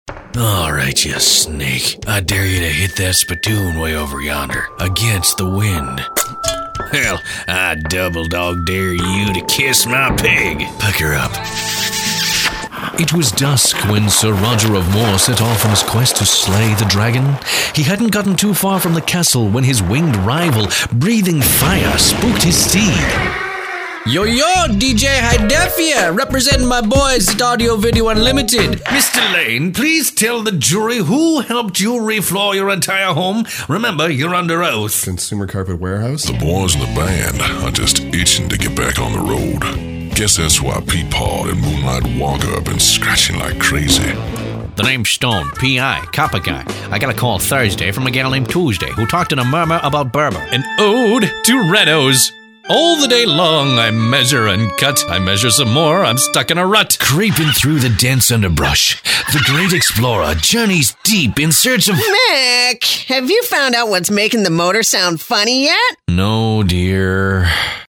Male
My natural voice is easily described as deep, authoritative or commanding.
Character / Cartoon
Words that describe my voice are voiceover, authoritative, professional.
1019Character_Voices.mp3